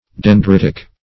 Dendritic \Den*drit"ic\, Dendritical \Den*drit"ic*al\, a.